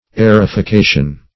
Search Result for " aerification" : The Collaborative International Dictionary of English v.0.48: Aerification \A`["e]r*i*fi*ca"tion\, n. [Cf. F. a['e]rification.
aerification.mp3